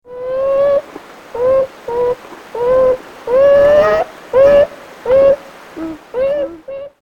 Звуки бобров
Бобровое урчание